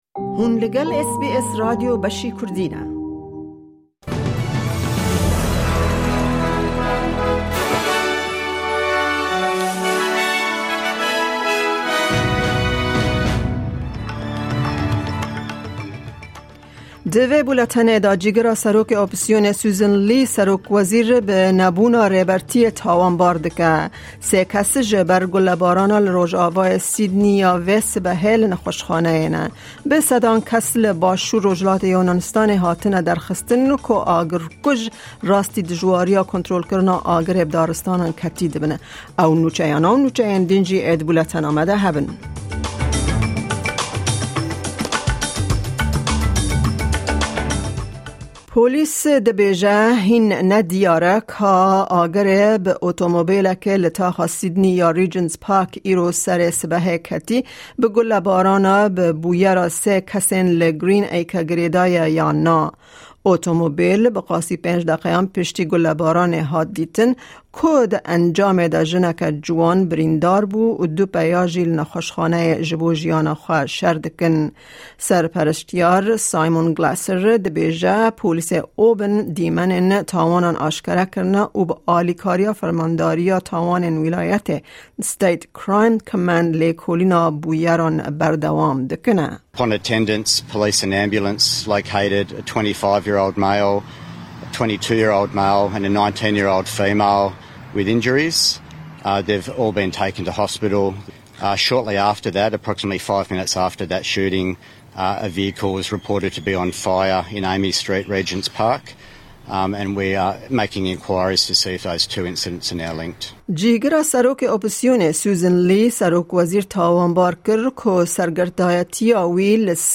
Kurdish News